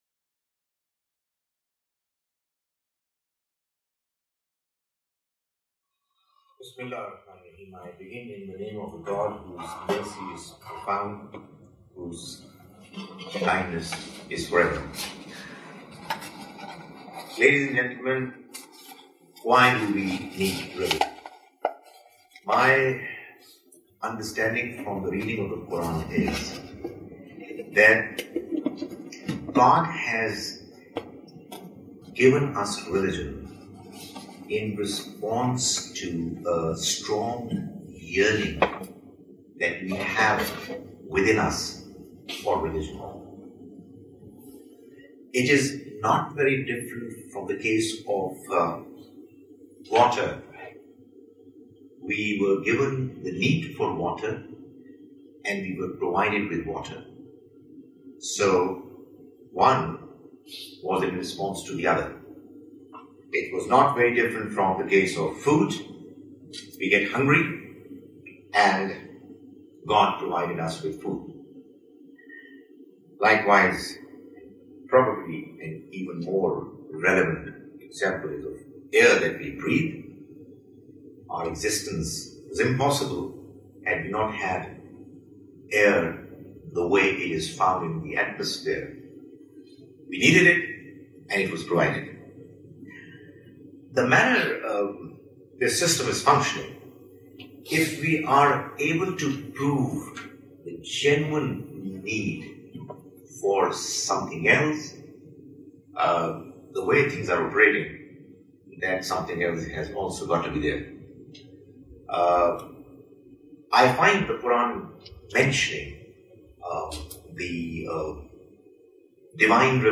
This video has been recording in Adelaide Australia on 02 May 2014.